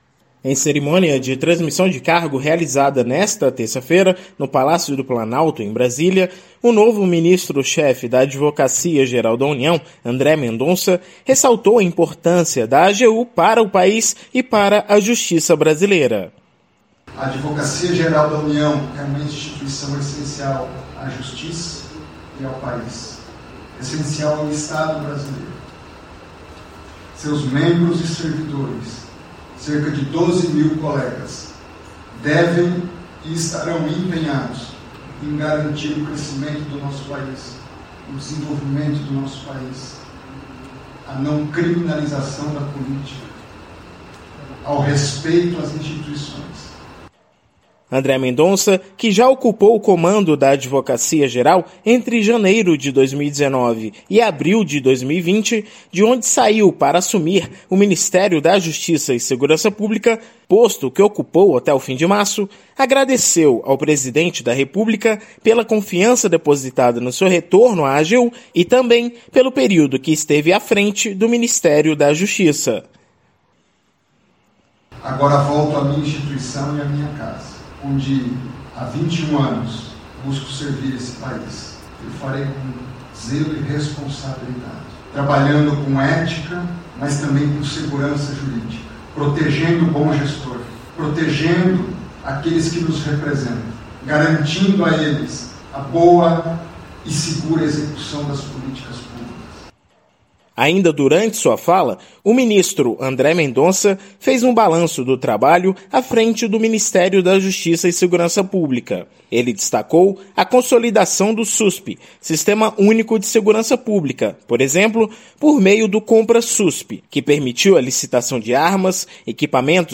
André Mendonça discursou na Cerimônia de Transmissão de Cargo de Ministros de Estado no Palácio do Planalto